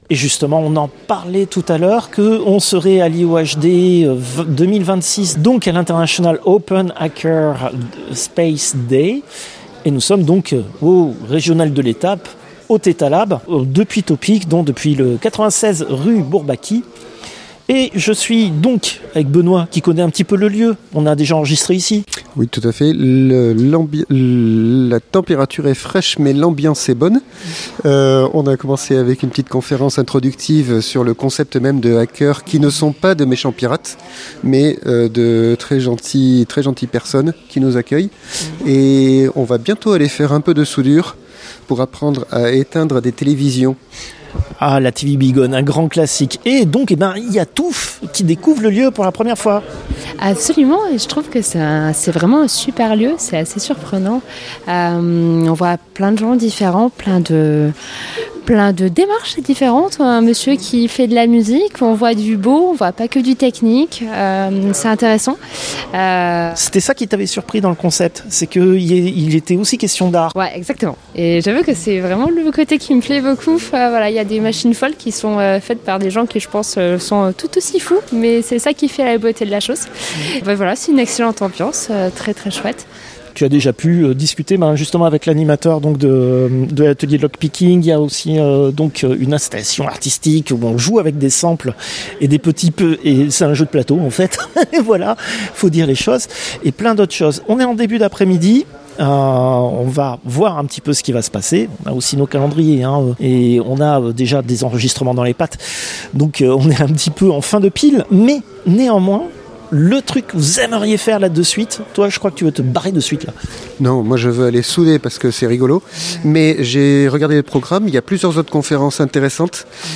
Extrait de l'émission CPU release Ex0241 : lost + found (avril 2026).